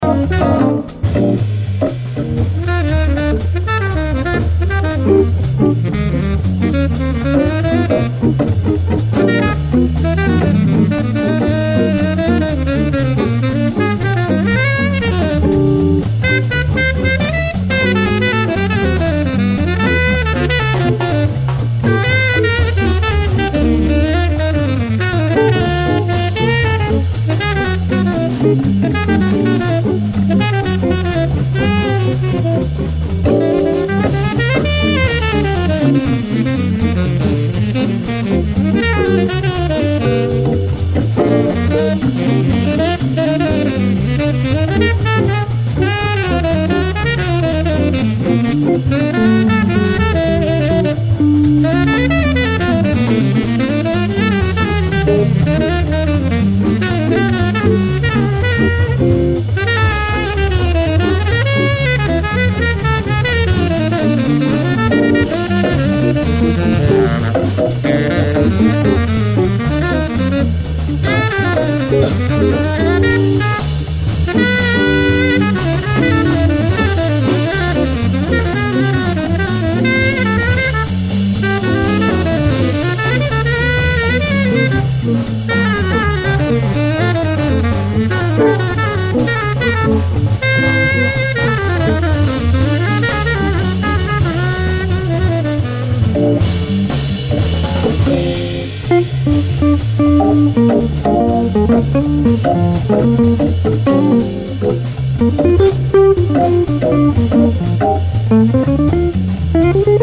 Ca groove, babe.
Le saxo est phénoménal et le reste aussi d'ailleurs !